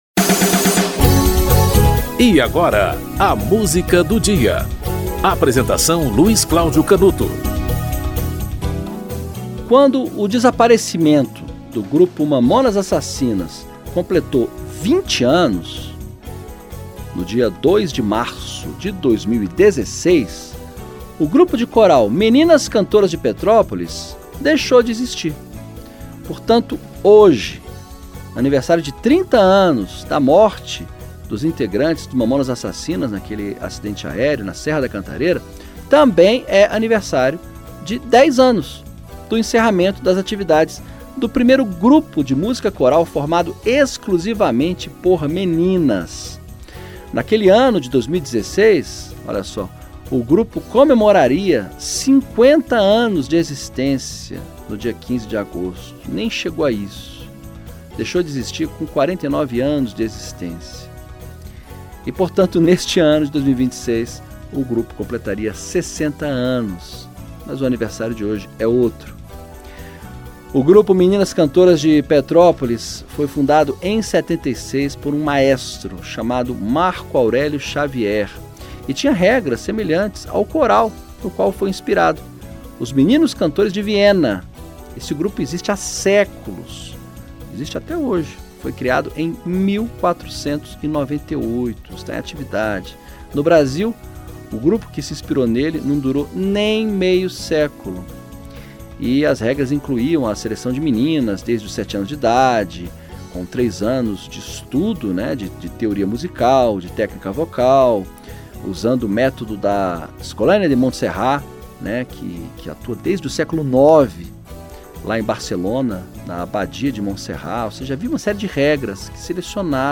Produção e apresentação: